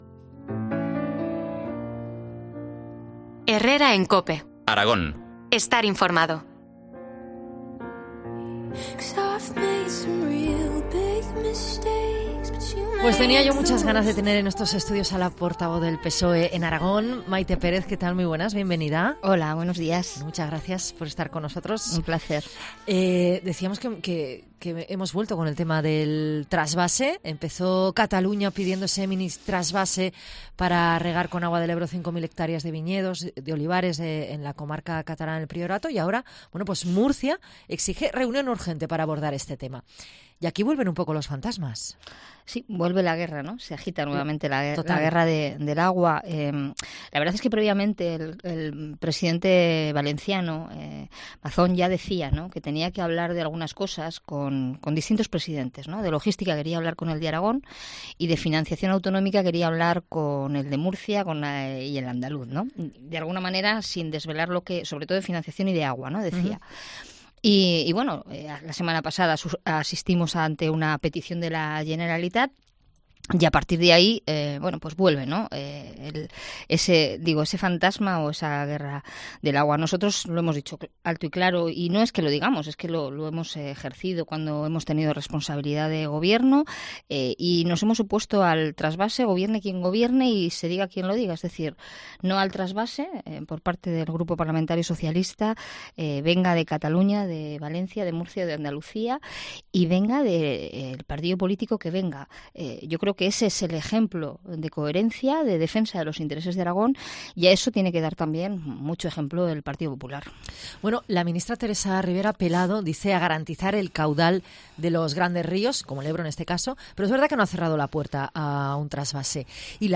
La portavoz del PSOE en las Cortes de Aragón ha pasado por Herrera en COPE Aragón para expresar el rechazo de su formación a cualquier trasvase procedente de la cuenca del Ebro
Entrevista a Mayte Pérez, portavoz del PSOE en las Cortes de Aragón